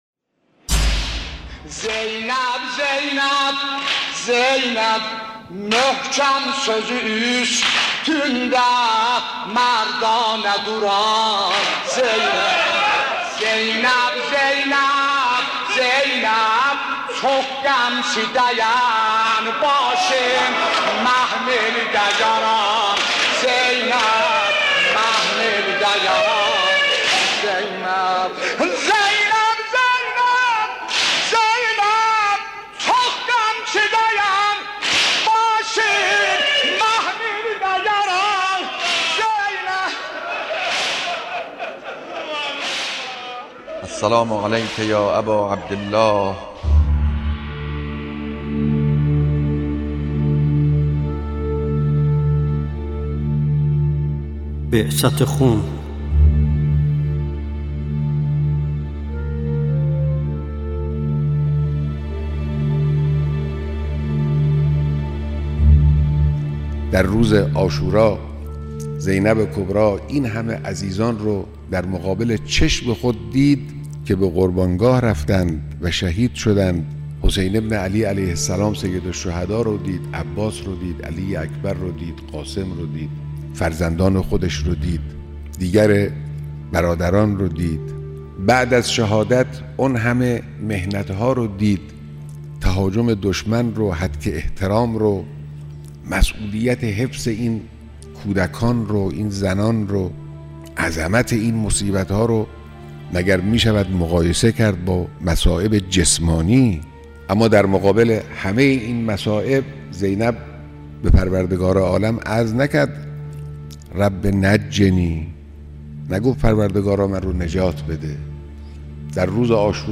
یازدهمین و آخرین برنامه از سری برنامه‌های سال دوم بعثت خون، « سمتِ بهشت » نام دارد که تلاش می‌کند بخش‌هایی از نقش قهرمانانه‌ی حضرت زینب کبری سلام‌الله علیها را در ماجراهای سال 61 هجری قمری و حوادث بعد از عاشورا روایت کند. ذکر مصیبت، بخش‌هایی از بیانات رهبر انقلاب درباره جایگاه و نقش حضرت زینب سلام‌الله علیها، مداحی، شعرخوانی و روایت تاریخی این رویداد